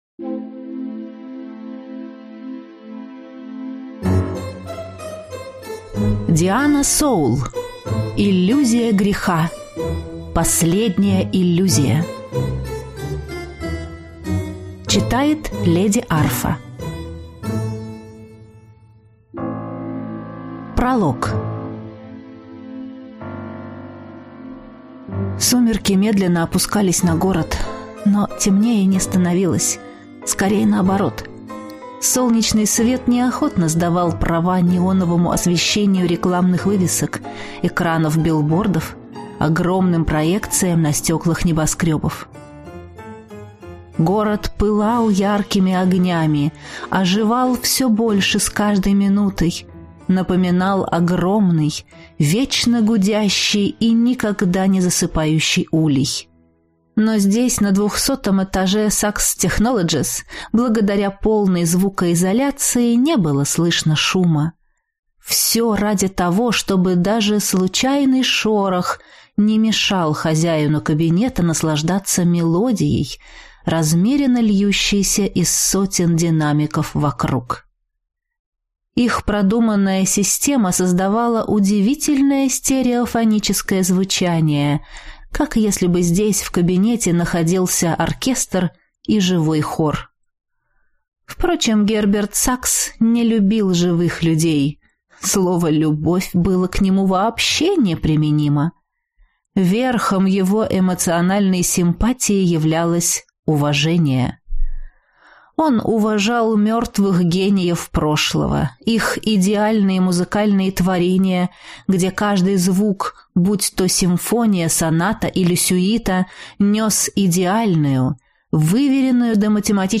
Аудиокнига Иллюзия греха. Последняя иллюзия | Библиотека аудиокниг